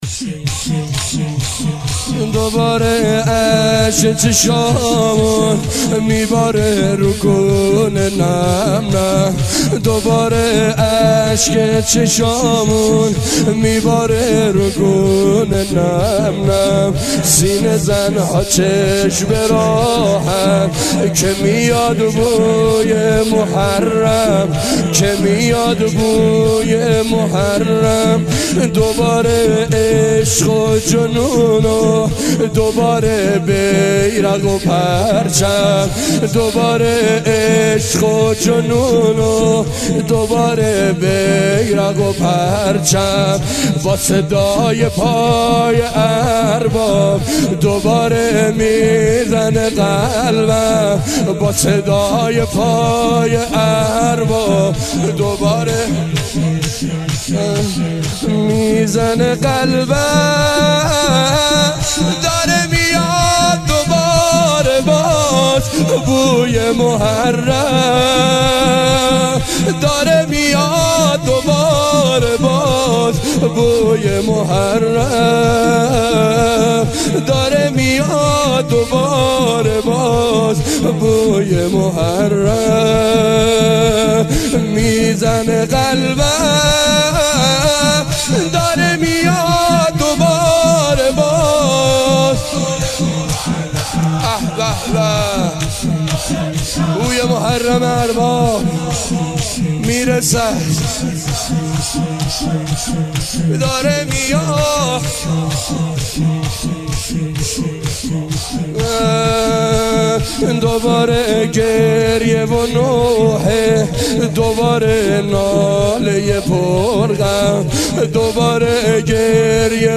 شور - چاووش محرم